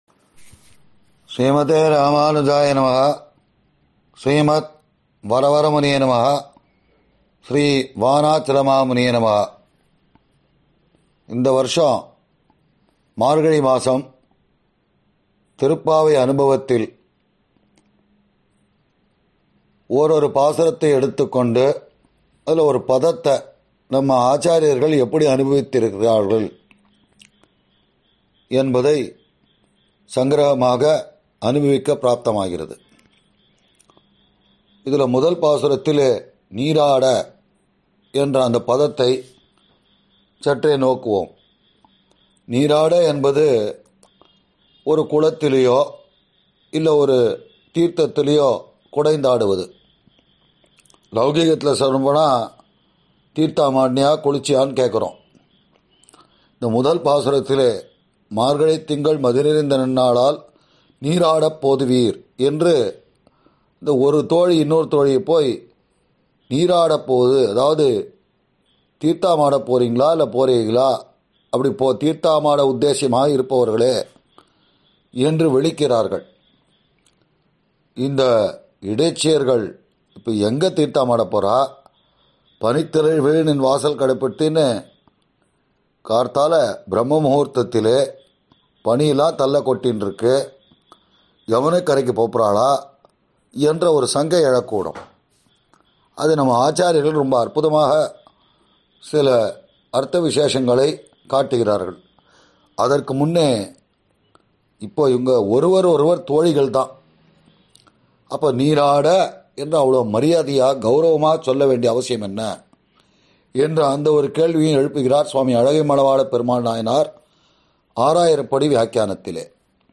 ஆறெனக்கு நின் பாதமே சரண் குழுமத்தினர் வழங்கும் சார்வரி ௵ மார்கழி ௴ மஹோத்ஸவ உபன்யாசம்